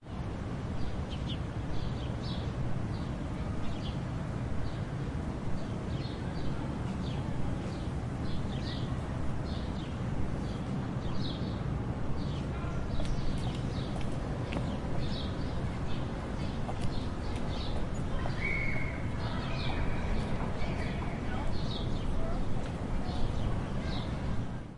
平静的郊区住宅中的空气色调
描述：记录在外面。
Tag: 背景声 房间噪音 空气 大气 suburbio 白噪声 airtone 背景 ATMO 氛围 卡尔马 冷静 ATMOS 和平 拉巴斯 城市 音景 常规 - 噪音 环境 远野 氛围 ruido 语气 气氛